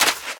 High Quality Footsteps
STEPS Sand, Run 03.wav